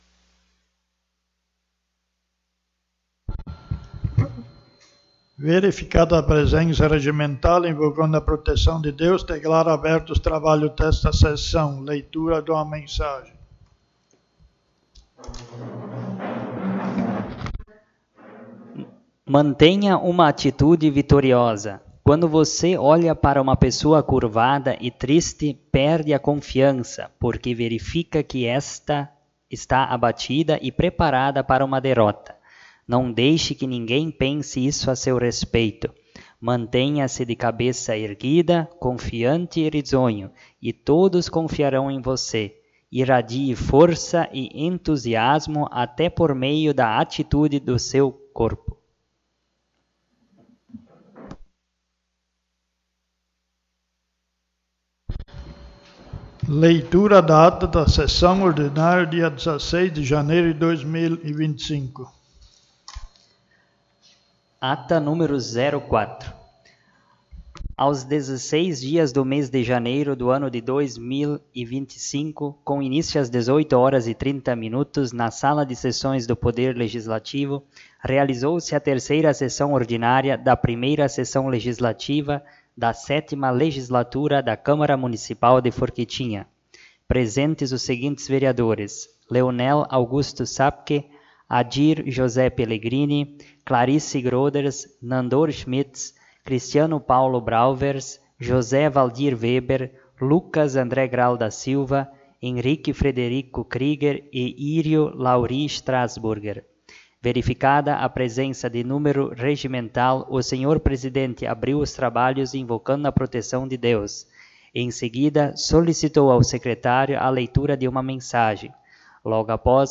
4ª Sessão Ordinária
O espaço do Expediente foi utilizado pelo vereador Adir José Pellegrini.
Câmara de Vereadores de Forquetinha